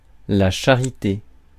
Ääntäminen
Ääntäminen France: IPA: [ʃa.ʁi.te] Haettu sana löytyi näillä lähdekielillä: ranska Käännös Ääninäyte Substantiivit 1. charity US Suku: f .